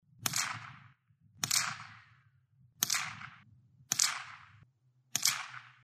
.22 Rifle
22_Rifle.mp3